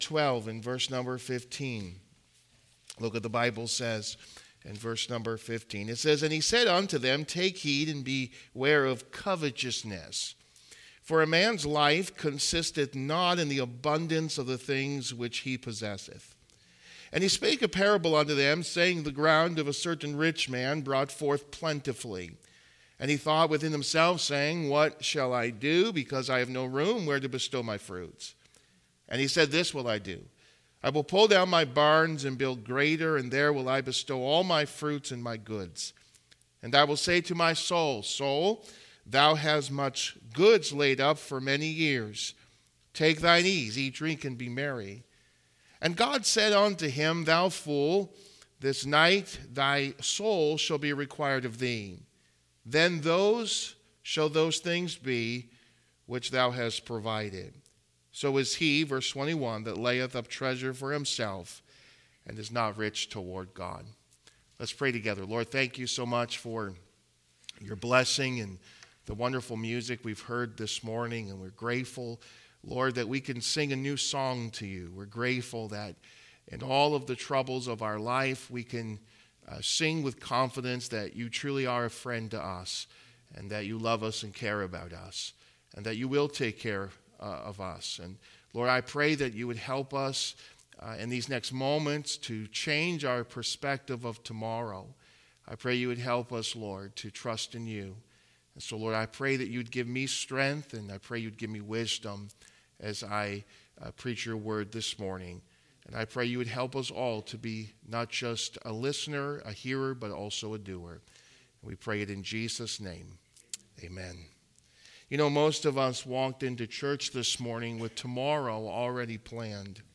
Trusting God with Tomorrow | Sermons